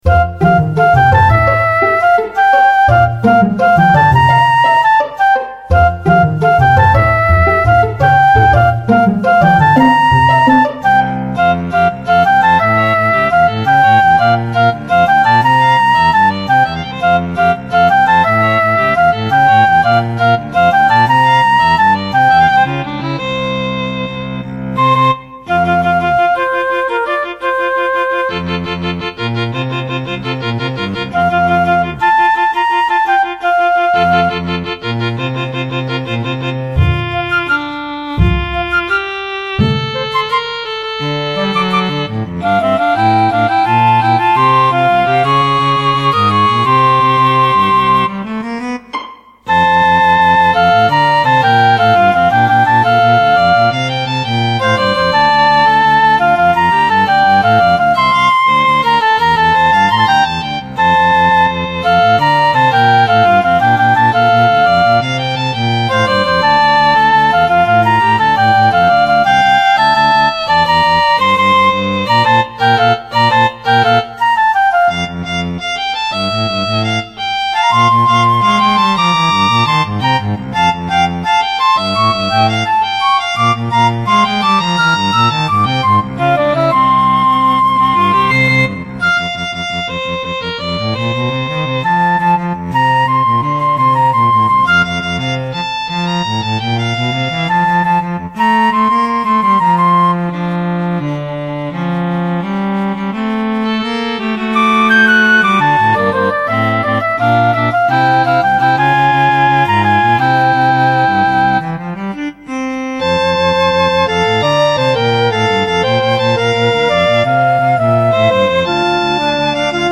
これをフルート、ヴァイオリン、チェロに編曲しろですって。
(ベタ打ち音源です) ↑120小節～はお気に入りです。